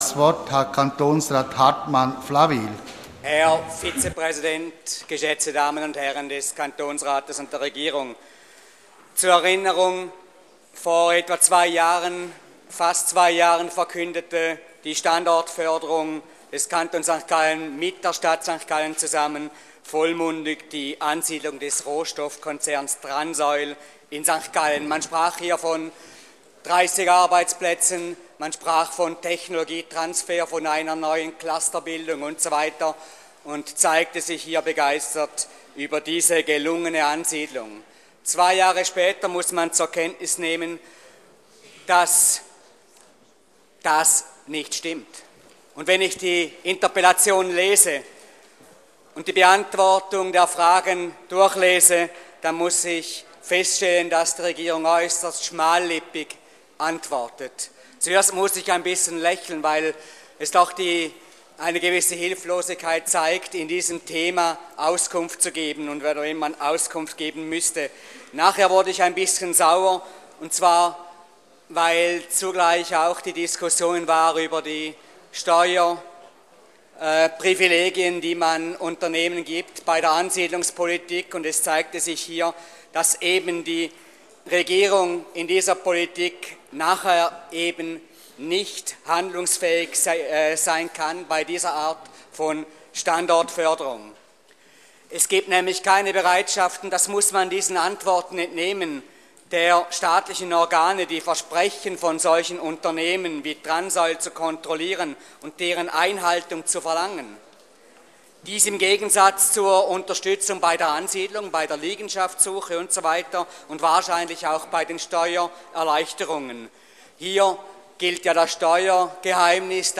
16.9.2013Wortmeldung
Session des Kantonsrates vom 16. bis 18. September 2013